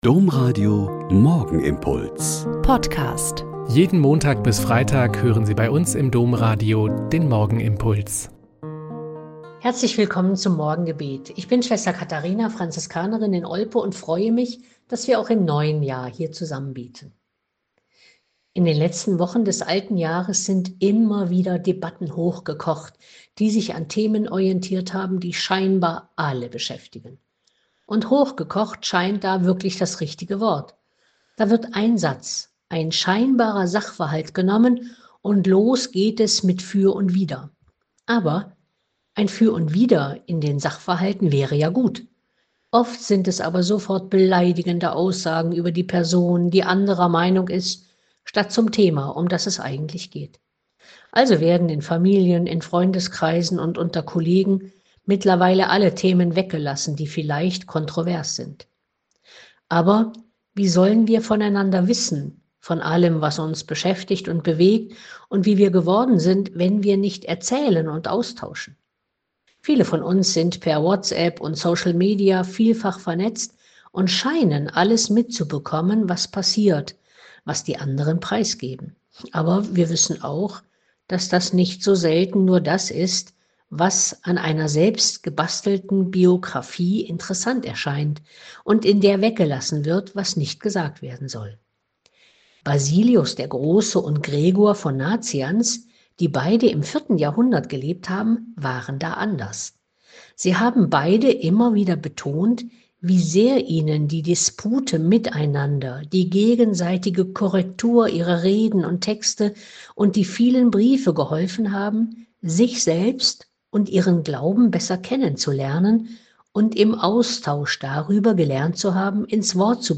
Joh 1,19-28 - Gespräch mit Tanja Kinkel - 02.01.2025